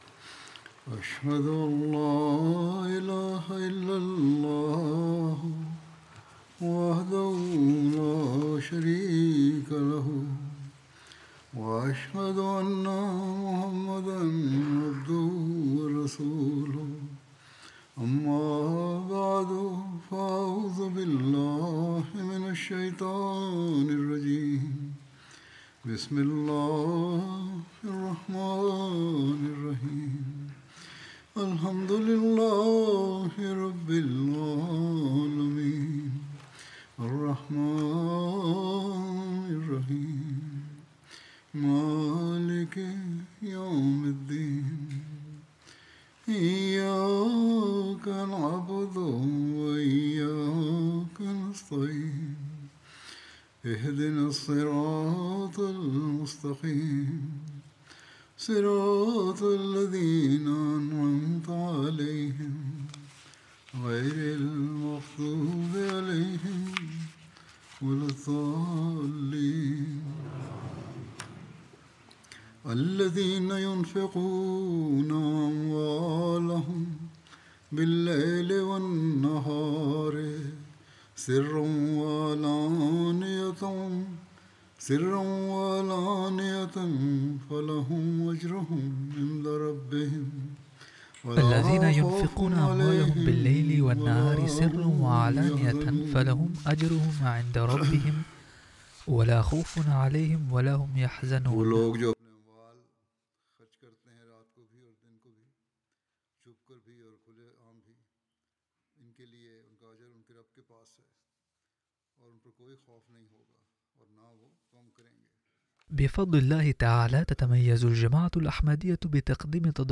Arabic translation of Friday Sermon